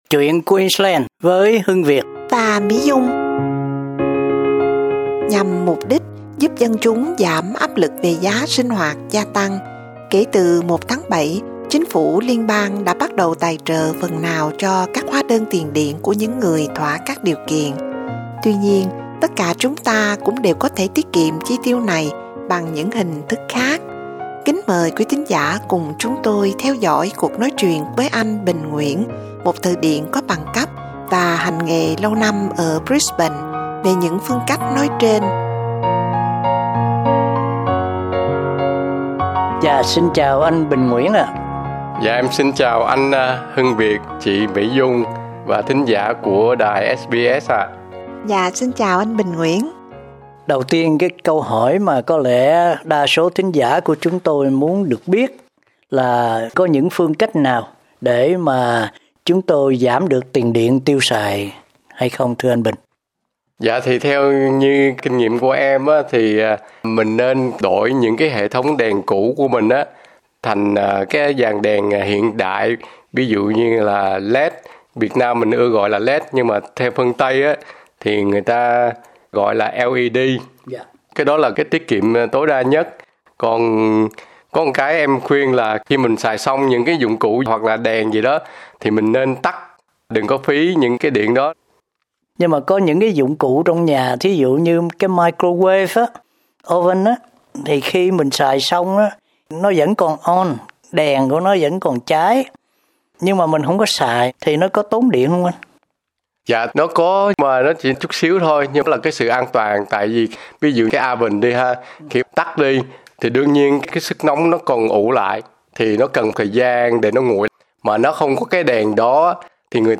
cuộc nói chuyện